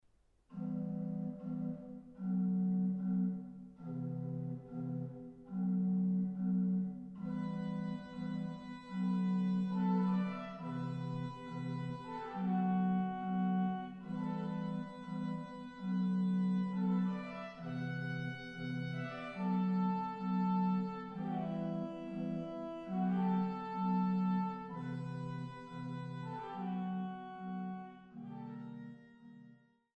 Neun Orgelstücke
Acht Stücke für Orgel solo